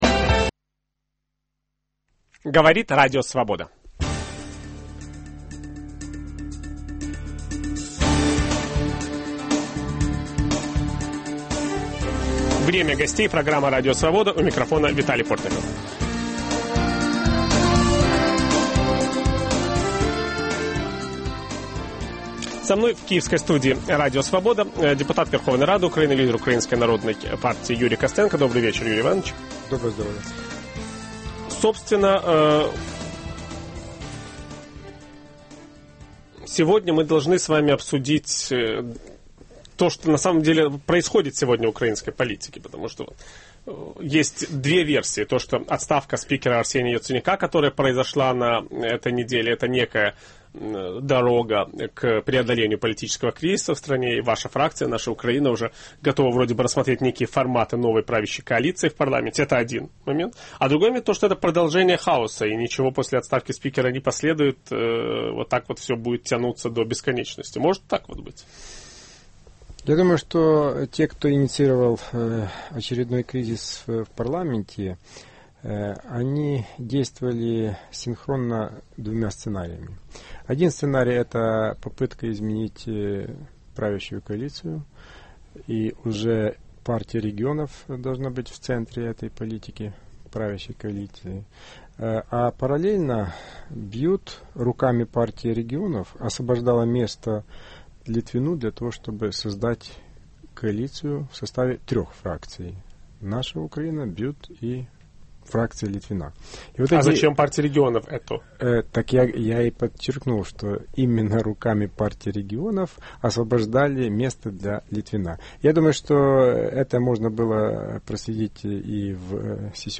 Появятся ли в украинском парламенте новый спикер и новое большинство? С ведущим программы Виталием Портниковым беседует депутат Верховной Рады Украины, лидер Украинской Народной Партии Юрий Костенко.